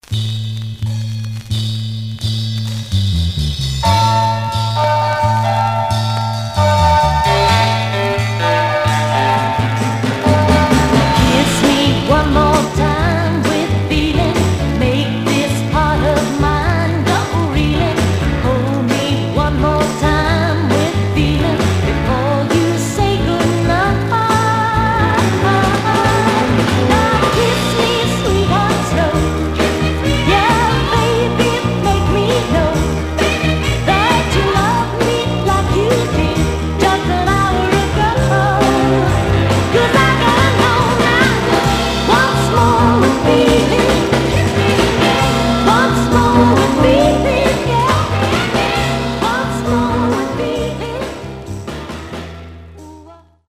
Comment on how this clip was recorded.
Mono